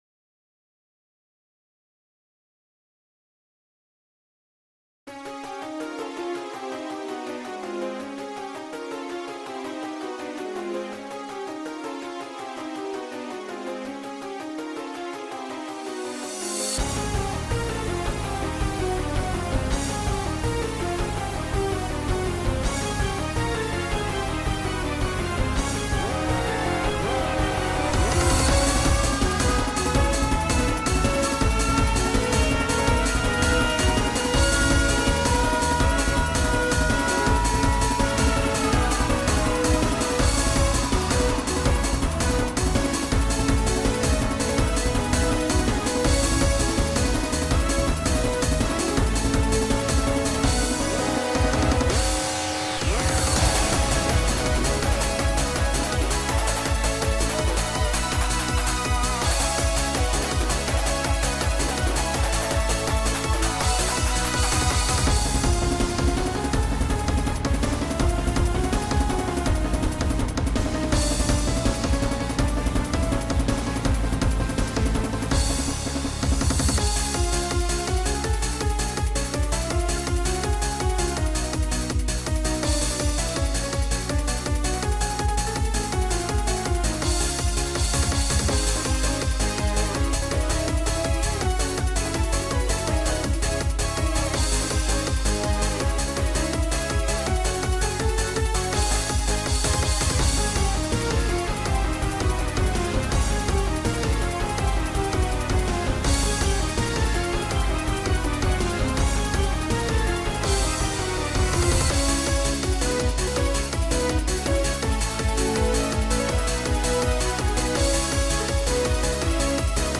BPM: 164 You can make a Simfile if you want to.
genre:eurobeat